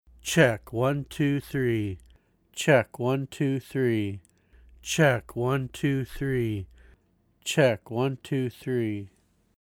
I run the signal either through my Golden Age Pre73jr into the Steinberg UR44 audio interface, or go straight into the interface preamps without the Golden Age pre in line.
In each sample, I say "Check one two three" four times.
The second sample mp3 compares the TLM102 the same way in the same sequence.
Overall, I prefer the sound of the TLM102 over the F-20 (for male voice).
View attachment TLM102, Steinberg pre, Golden Age pre.mp3